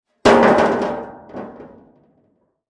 Metal_24.wav